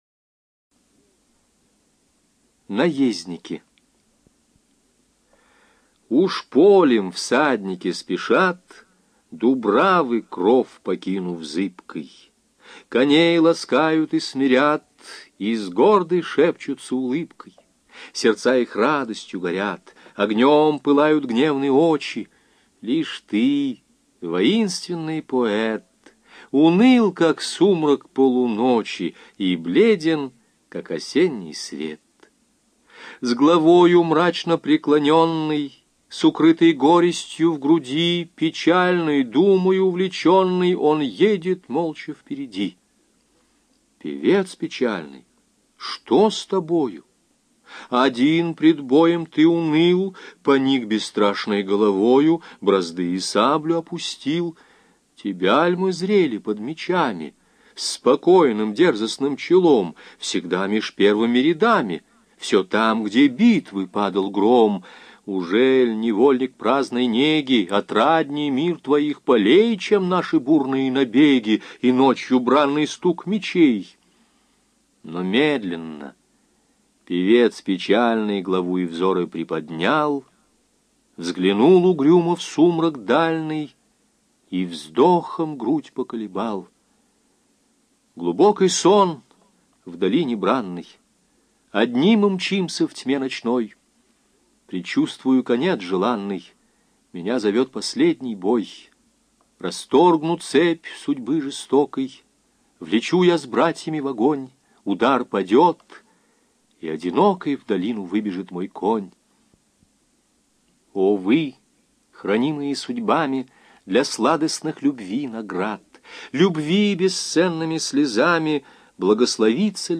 Автор читает текст из книги за 1959—1962 (А. С. Пушкин. Собрание сочинений в 10 томах, том 1), где представлена рецензированная версия, начинающаяся строкой «Уж полем всадники спешат».